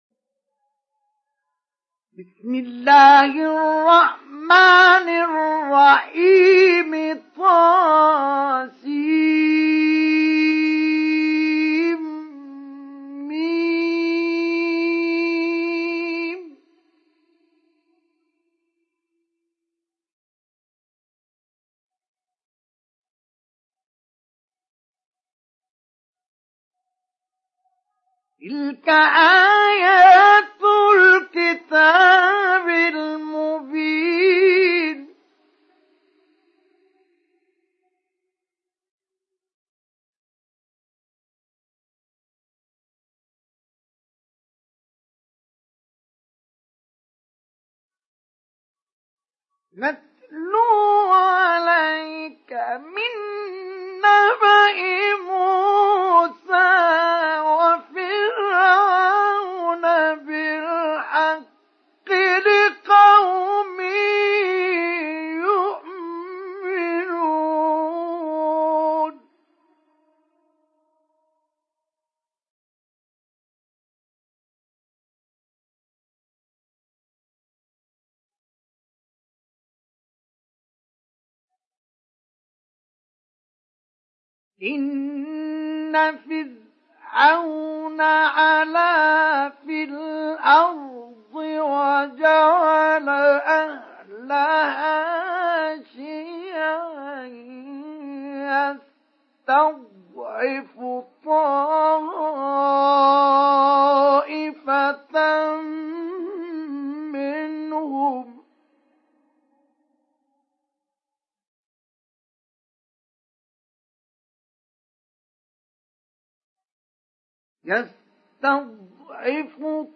Download Surat Al Qasas Mustafa Ismail Mujawwad